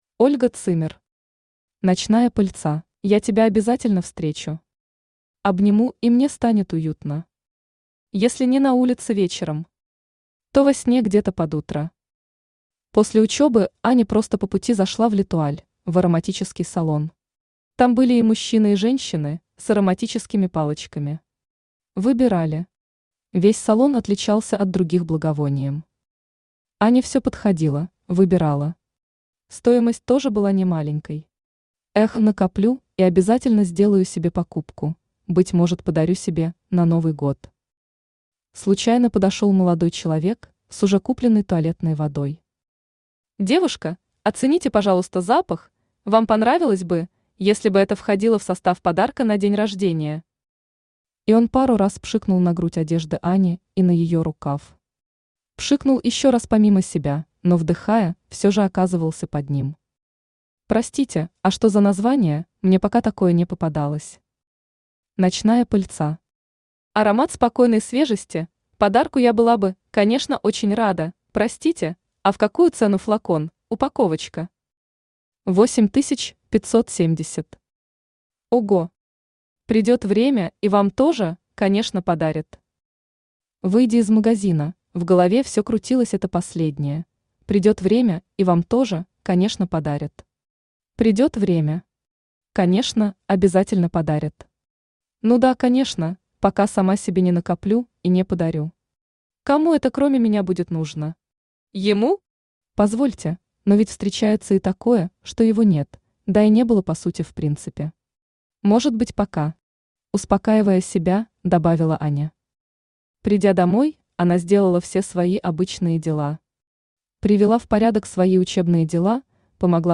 Аудиокнига Ночная пыльца | Библиотека аудиокниг
Aудиокнига Ночная пыльца Автор Ольга Zimmer Читает аудиокнигу Авточтец ЛитРес.